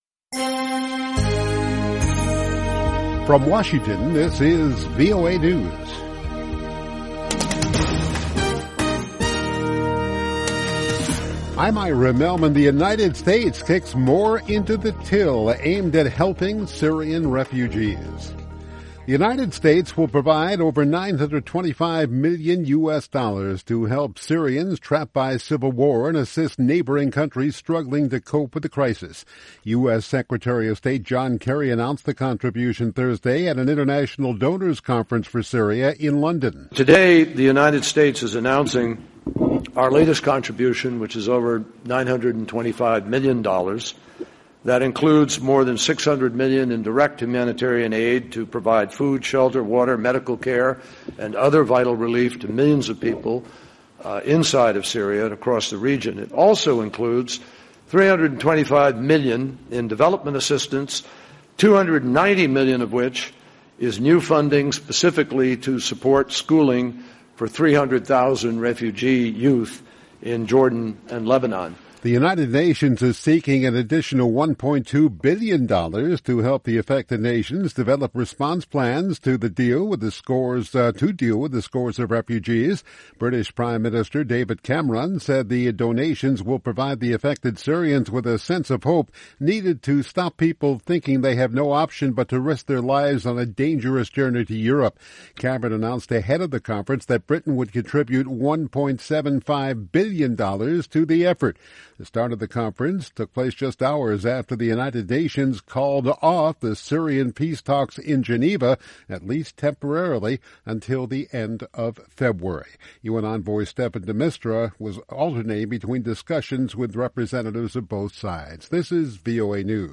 Embed share VOA English Newscast: 1400 UTC February 4, 2016 by VOA - Voice of America English News Embed share The code has been copied to your clipboard.